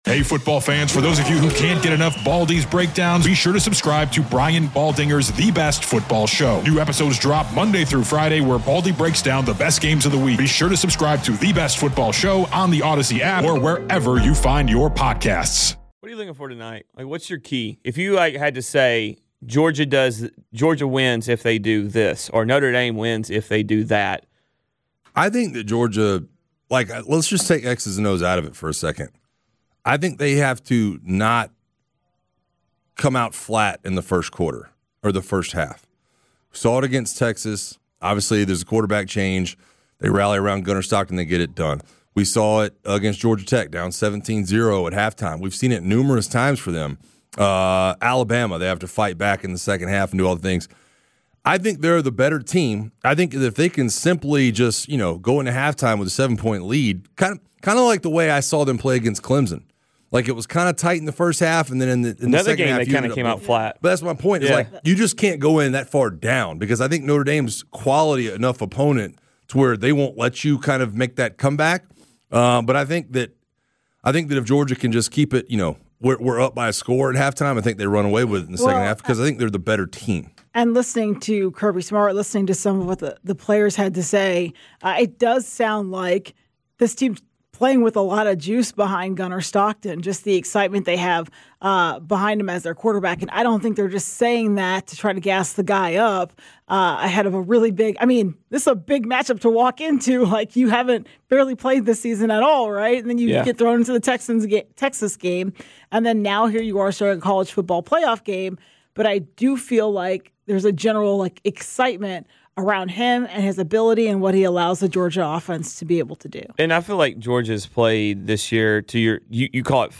“The Morning Shift on 92.9 The Game” is a show where passionate Atlanta sports fans connect for three hours of informative, entertaining, and funny talk radio.